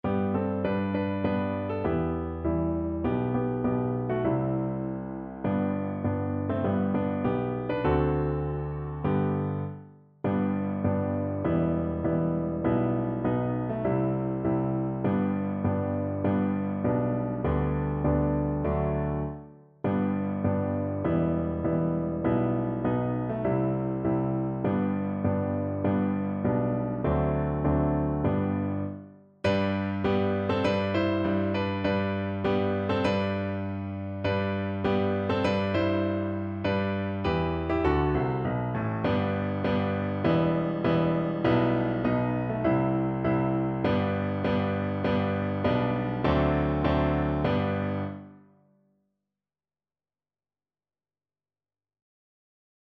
Moderato
4/4 (View more 4/4 Music)